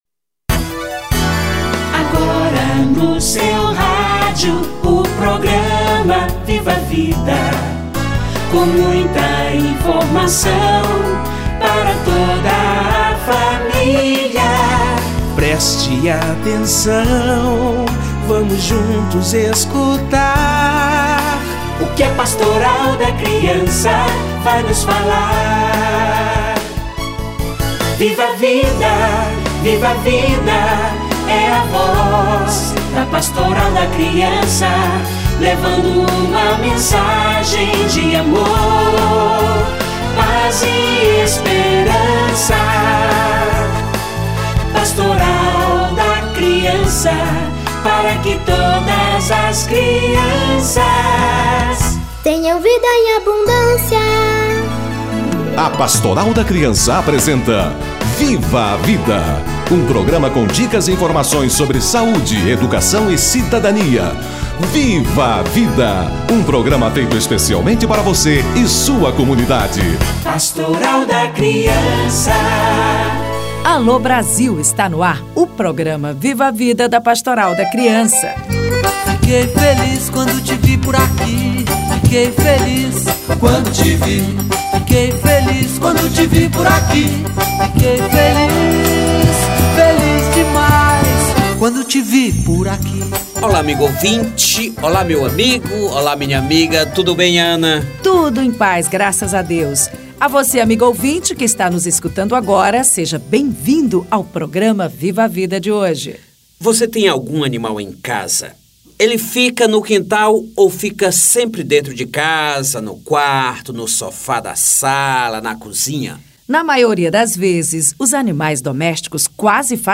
Gestantes, crianças e animais - Entrevista